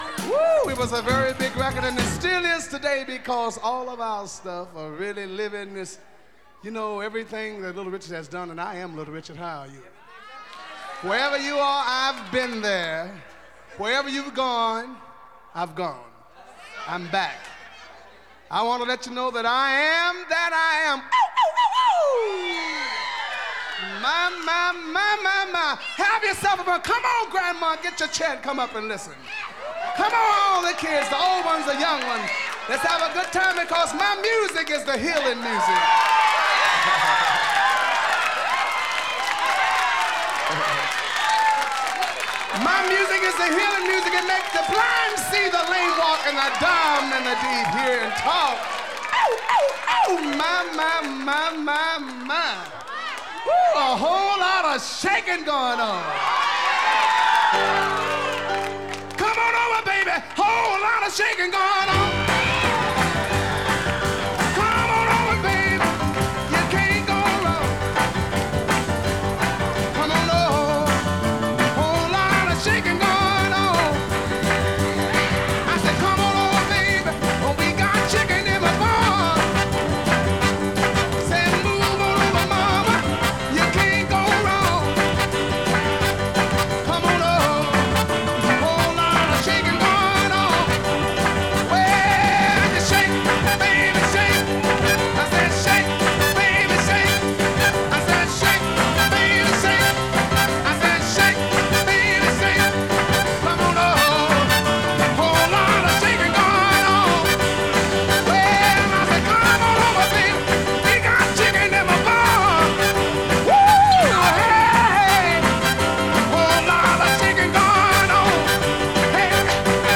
Жанр Рок-н-ролл, госпел